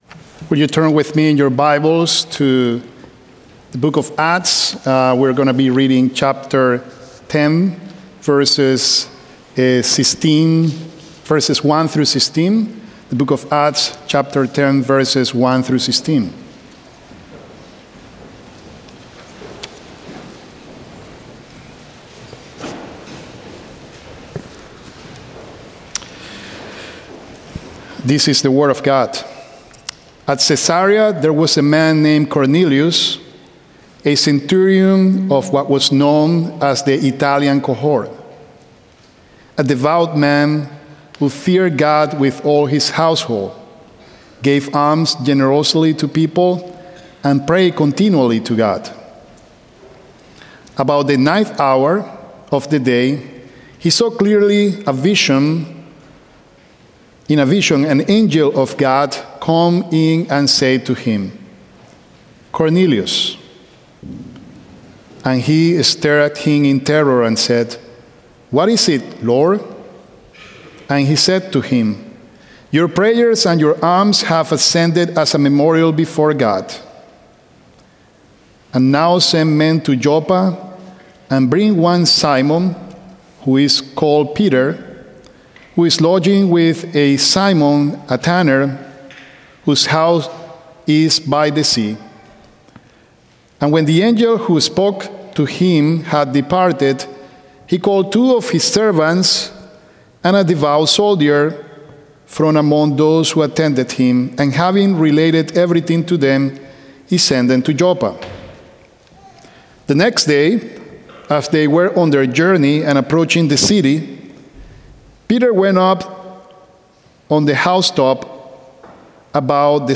10th - Tenth Presbyterian Church (PCA)
Journey to Jesus | SermonAudio Broadcaster is Live View the Live Stream Share this sermon Disabled by adblocker Copy URL Copied!